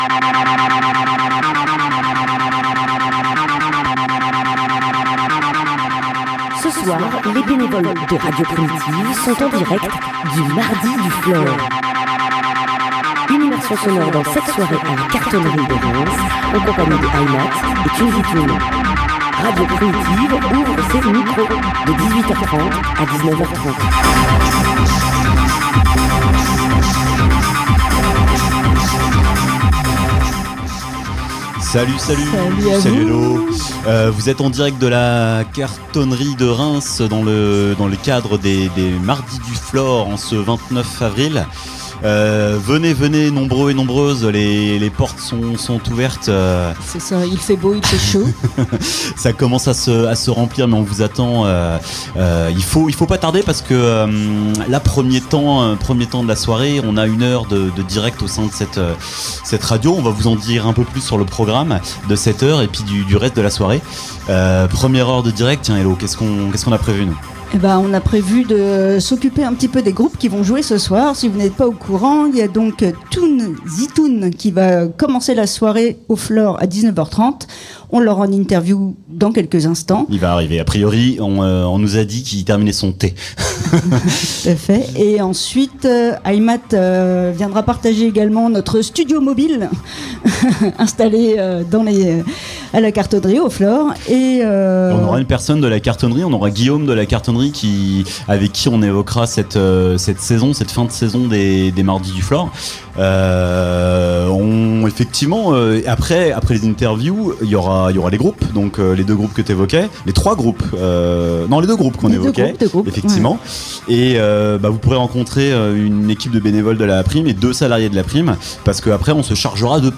Émission à La Cartonnerie du 29/04/2025